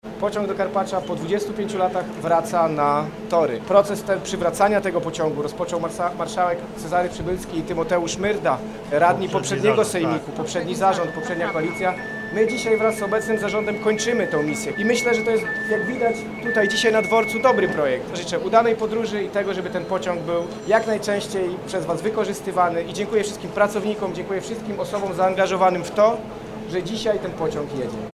To coś więcej niż nowe połączenie w rozkładzie jazdy – to początek nowego sposobu myślenia o podróżowaniu po Polsce, a za razem szansa na rozwój dla mniejszych miejscowości i renesans dla całej idei podróży pociągiem do górskich kurortów – dodaje Michał Rado, Wicemarszałek Województwa Dolnośląskiego odpowiedzialny za rozwój kolei.